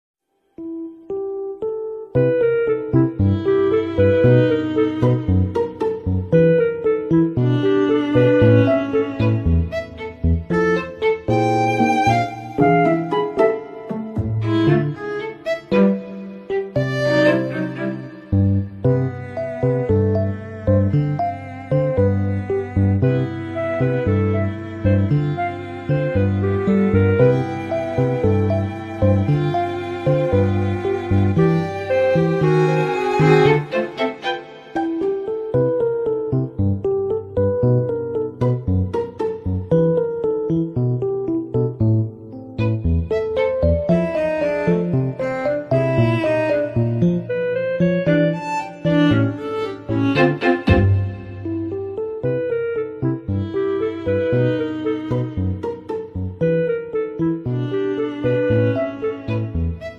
finally trying some voice dubbing sound effects free download
WITH!! COOL VOICE ACTORS WOW!!!!!! credits incase yall don't watch it through